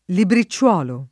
libricciolo [libri©©0lo] (oggi lett. libricciuolo [